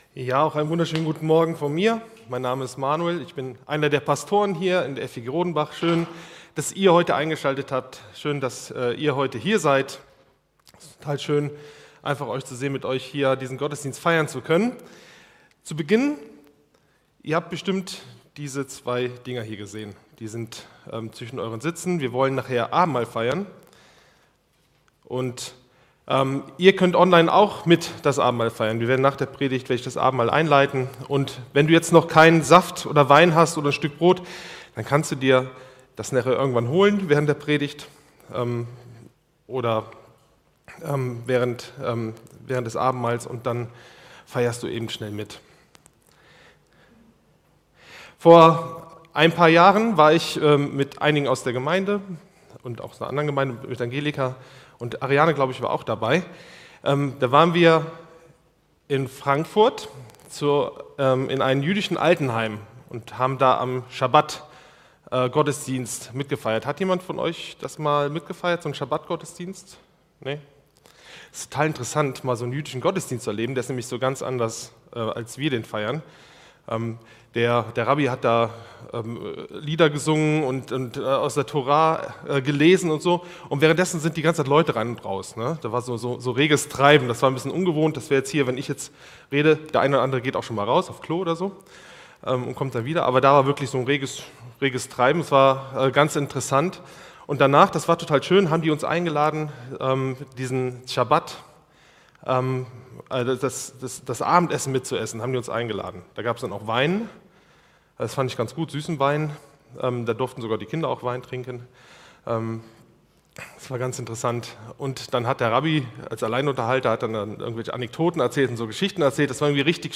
„Jesus und: Sabbat“ | Gottesdienst